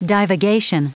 Prononciation du mot divagation en anglais (fichier audio)
Prononciation du mot : divagation